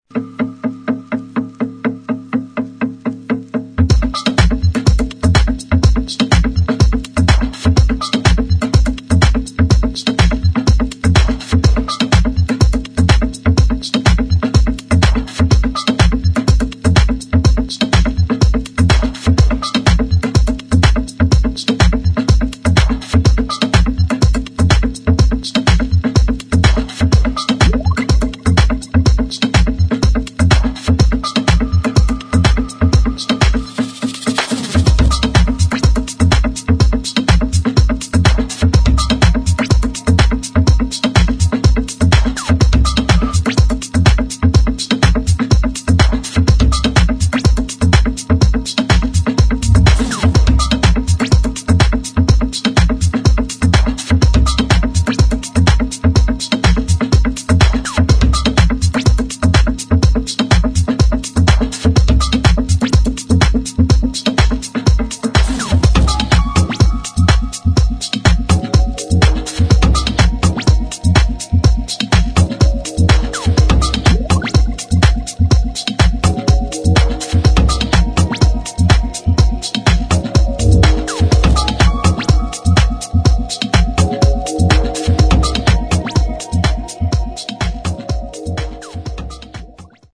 [ DEEP HOUSE / DISCO ]